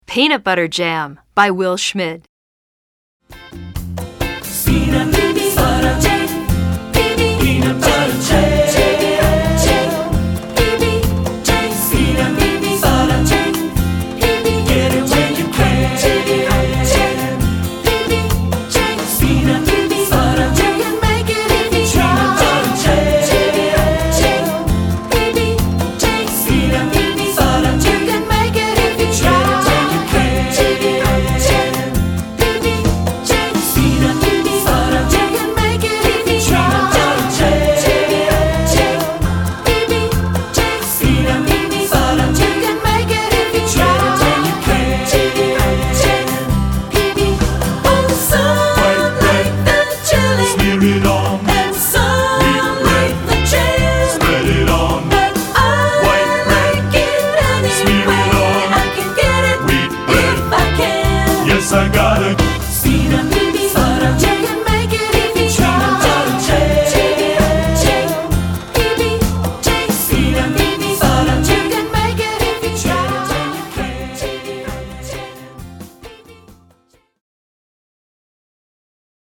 Voicing: 4-Part